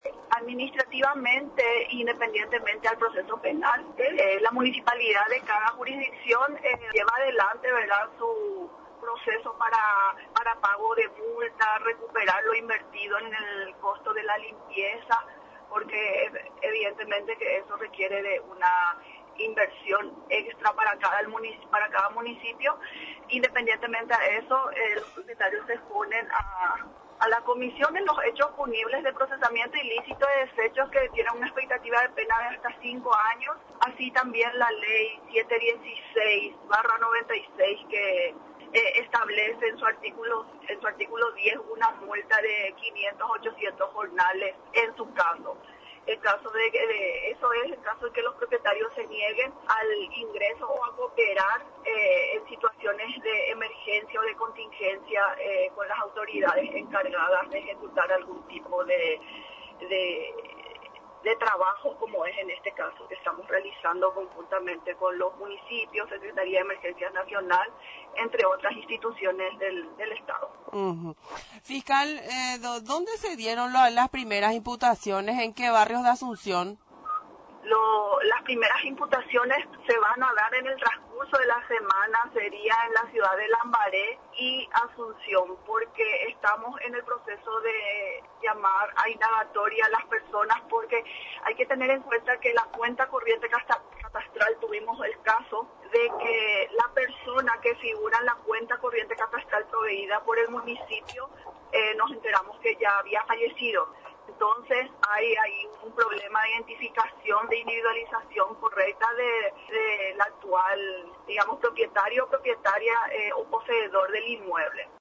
Con el objetivo de eliminar los criaderos de mosquitos, el Ministerio Público, procedió a sancionar con penas pecuniarias y procesos penales, a los dueños de patios baldíos, por no limpiar sus terrenos, informó este miércoles la fiscal de Medio Ambiente, Liza Martínez.